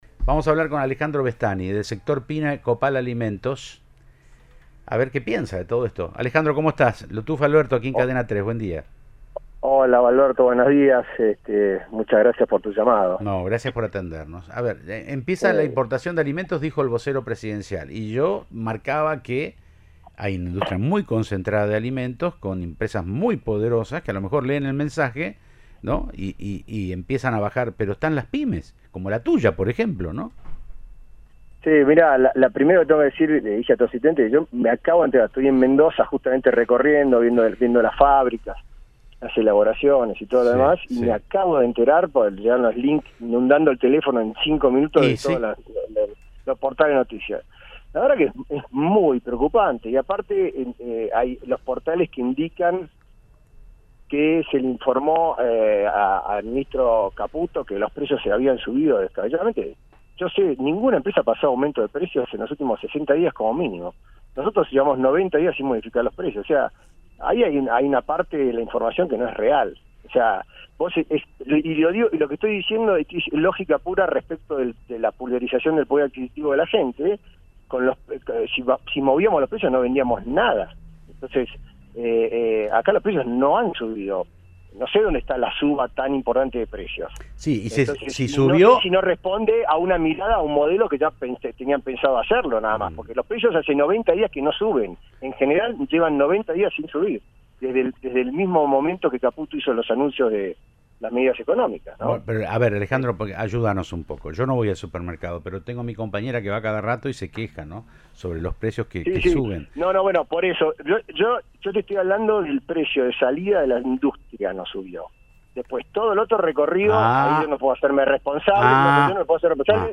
Radio